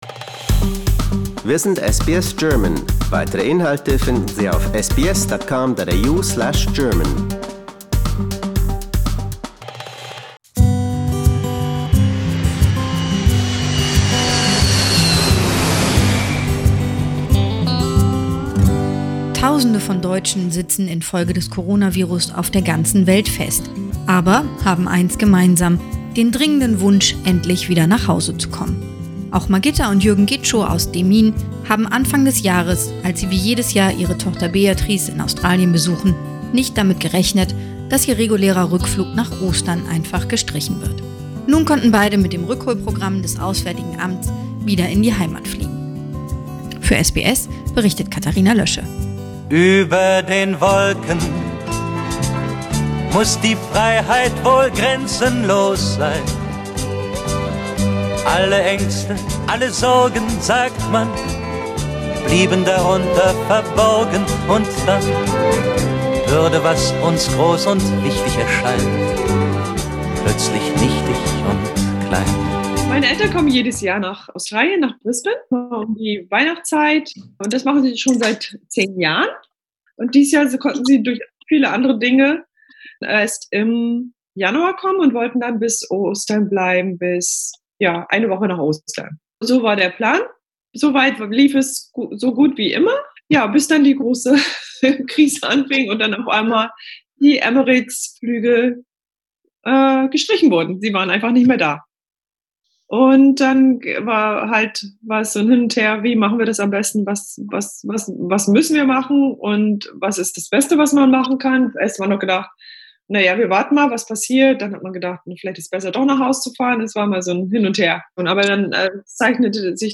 berichtet aus Brisbane darüber